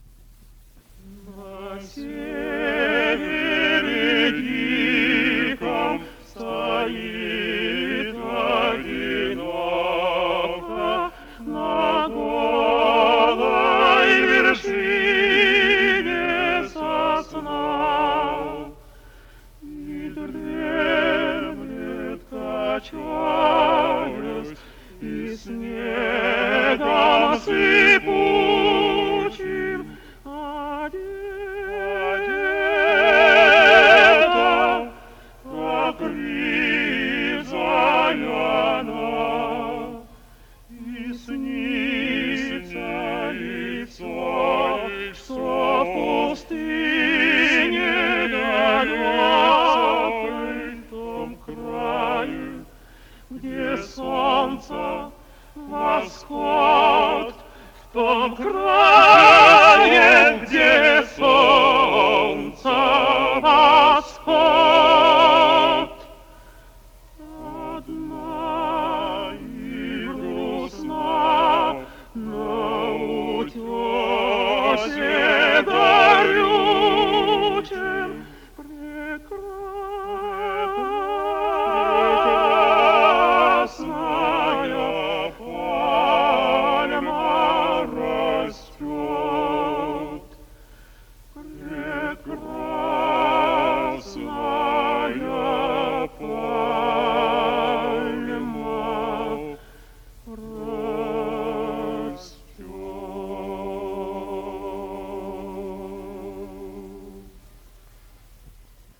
с профессиональной магнитной ленты
Скорость ленты76 см/с
Тип лентыAgfa Wolfen Typ C